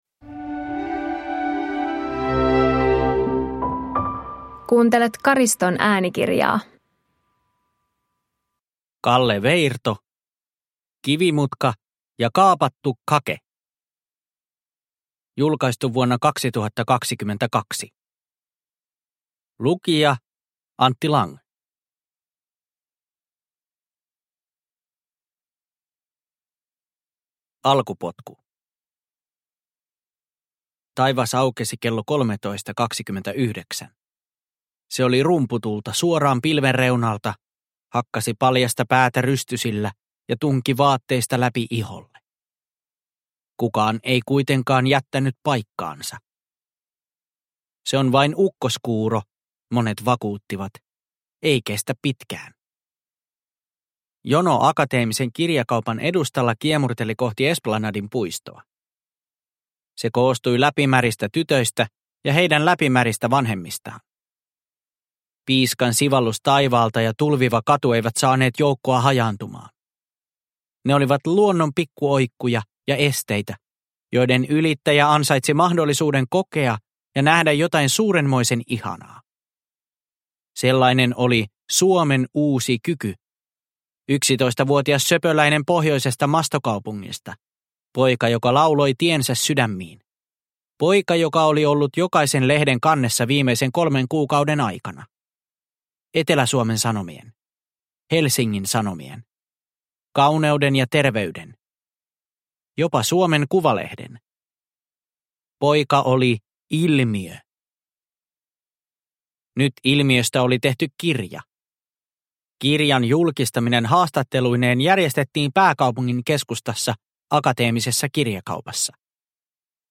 Kivimutka ja kaapattu Cake – Ljudbok